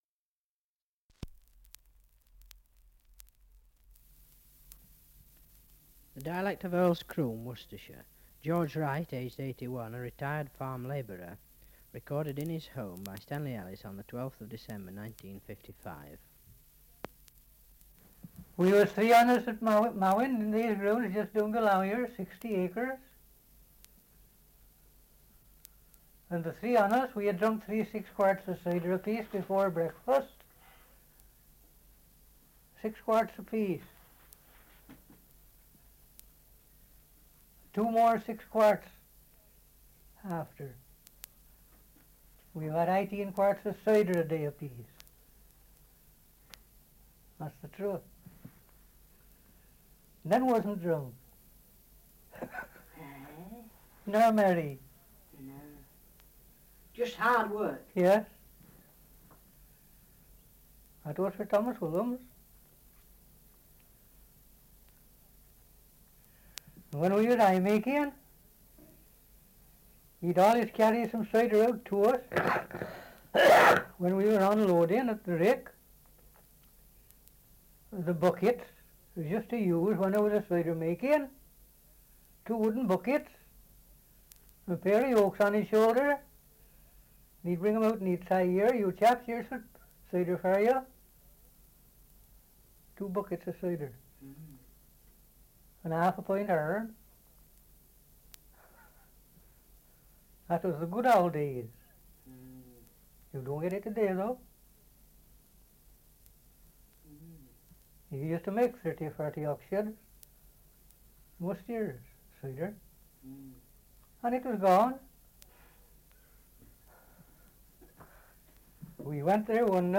Survey of English Dialects recording in Earl's Croome, Worcestershire
78 r.p.m., cellulose nitrate on aluminium